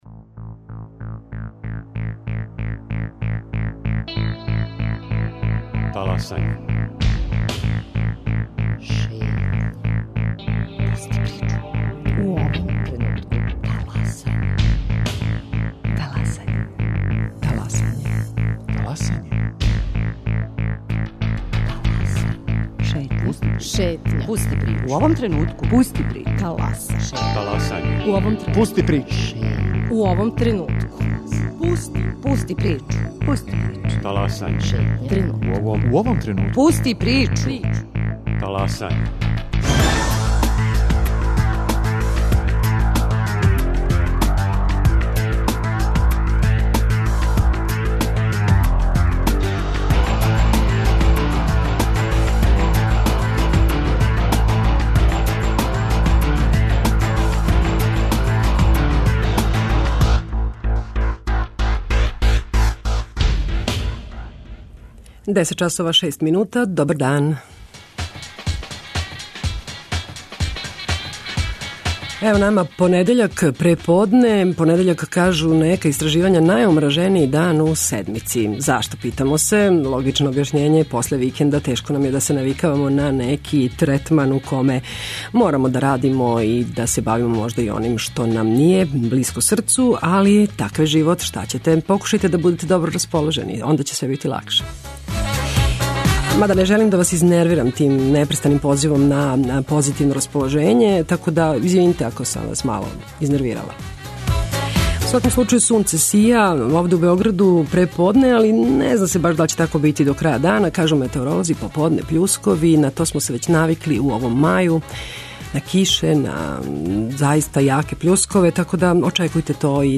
Гости Шетње су чланови удружења Микроарт.